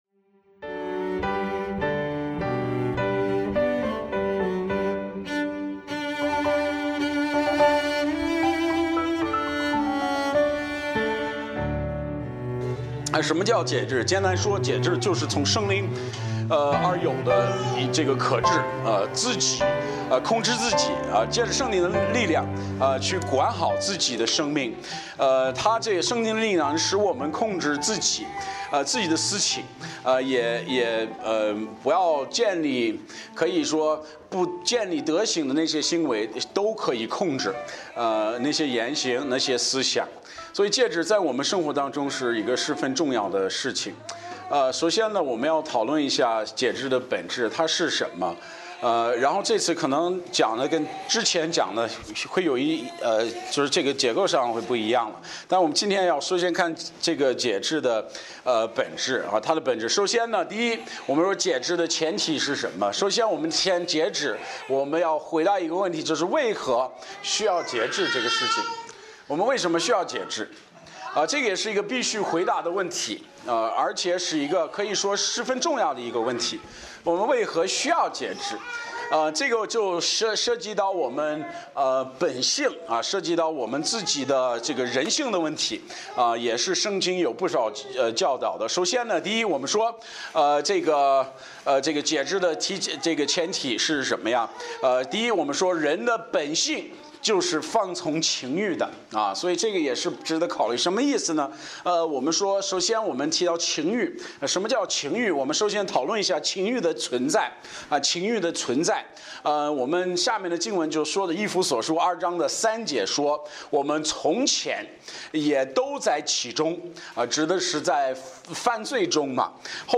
Bible Text: 迦拉太书5:22-23 | 讲道者